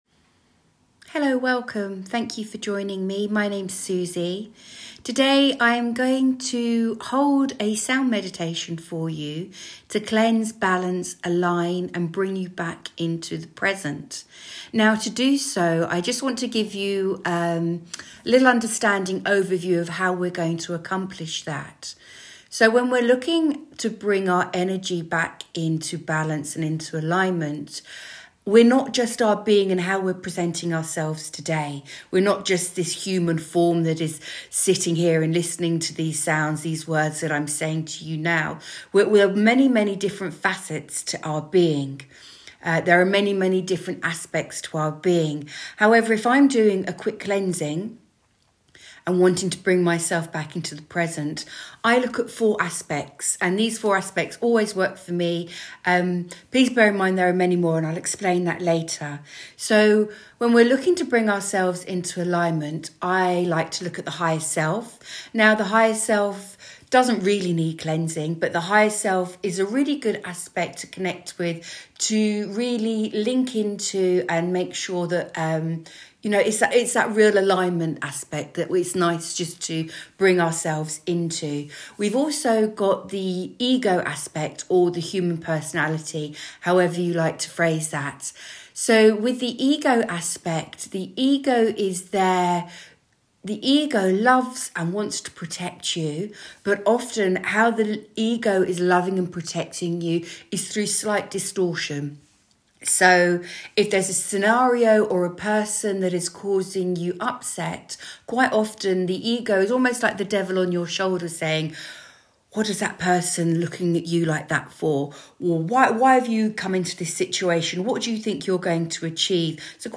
Free Meditations & Sound Healings - Enjoy my range of free meditations for adults and children to relax, reconnect and reenergise to.